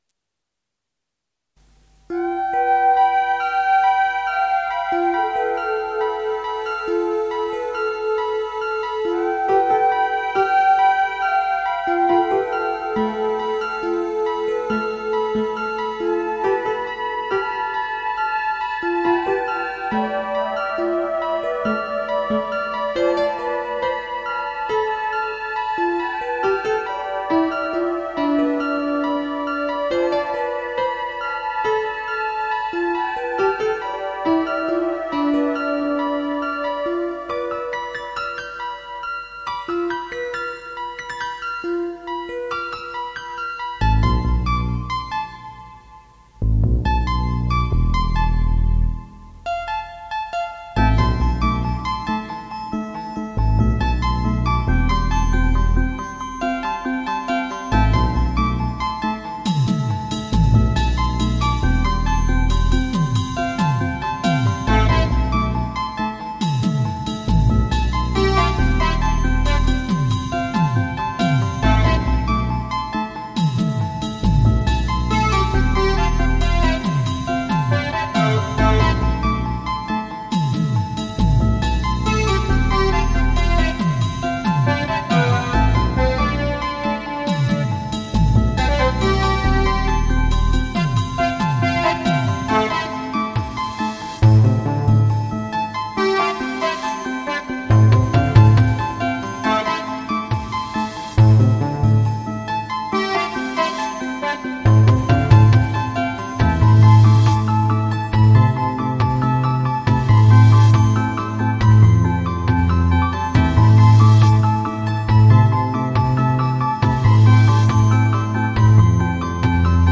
Work in electronic music experimentations
Most of my music was produced in an old Atari machine
with a midi clavier and sound software of the 90ies,